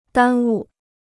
耽误 (dān wu) Free Chinese Dictionary